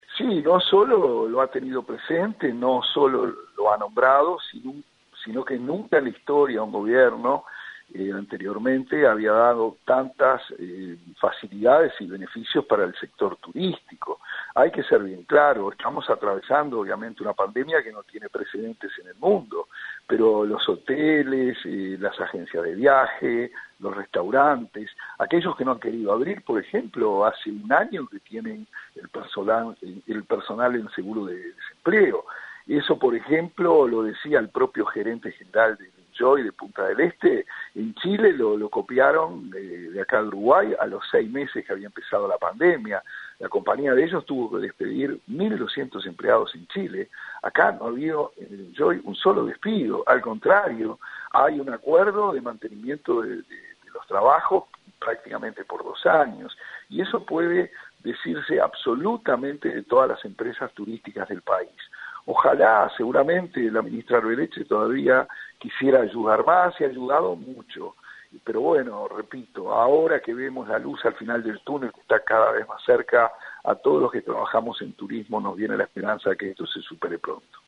Escuche un fragmento de lo expuesto por Remo Monzeglio sobre el panorama económico del sector turístico y la administración de Arbeleche